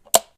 switch16.wav